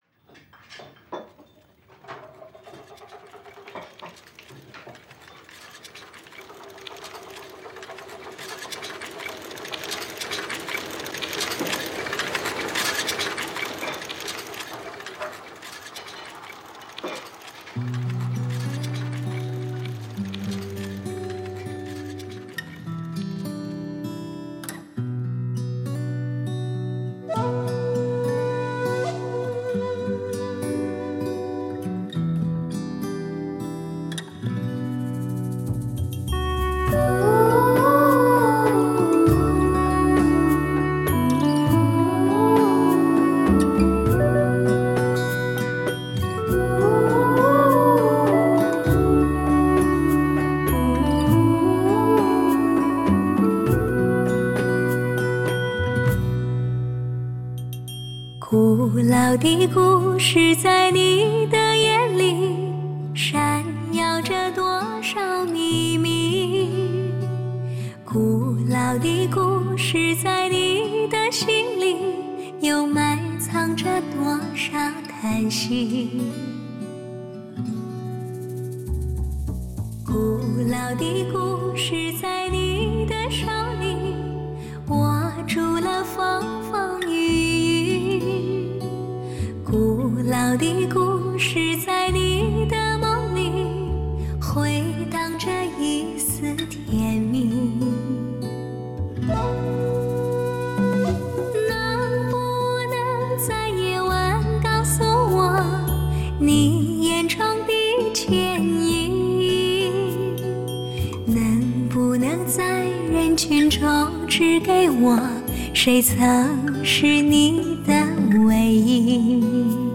一种诗化的声音 拨动心灵深处的弦